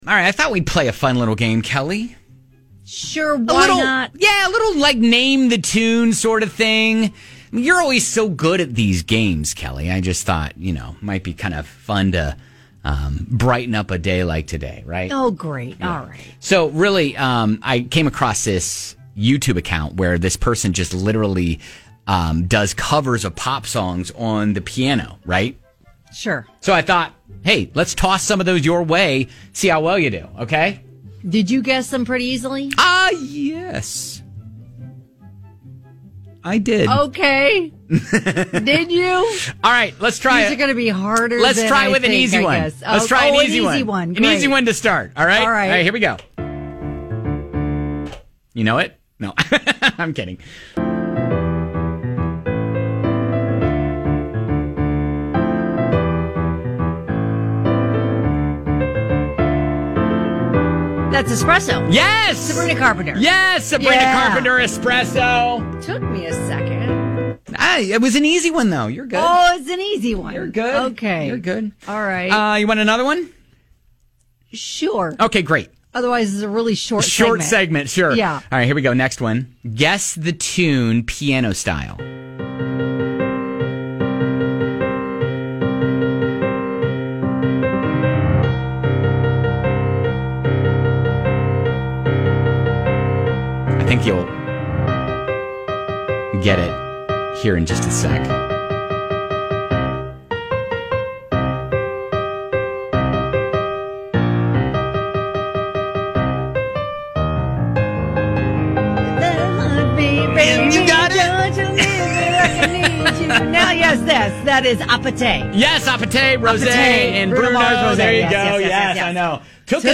Can you name the pop song based purely on the piano version??